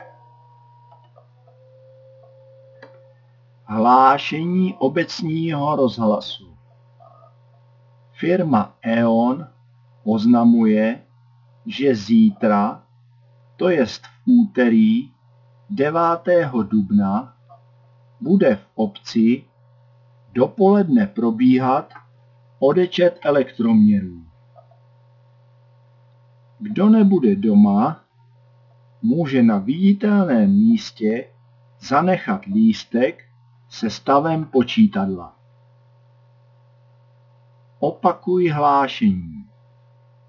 hlaseni-odecet-elektromeru.wav